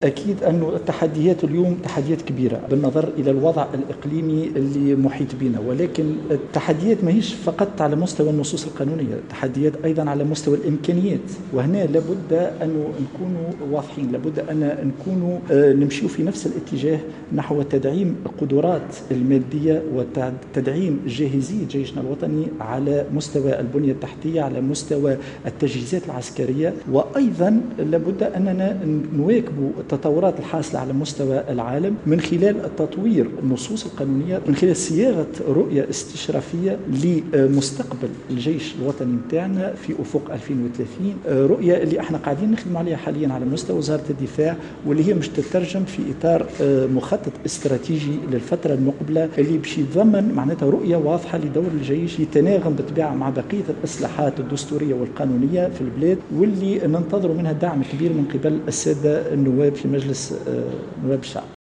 وأضاف اليوم على هامش إشرافه على اختتام الدورة التكوينية بمعهد الدفاع الوطني لفائدة أعضاء اللجنة الخاصة للأمن والدفاع بمجلس نواب الشعب، أن التحديات المطروحة لا تقتصر فقط على تطوير النصوص القانونية وإنما تهم خاصة تطوير البنية التحتية والرفع من قدرات وجاهزية الجيش الوطني والارتقاء بمنظومة الإحاطة الاجتماعية والدعم المادي والمعنوي للعسكريين.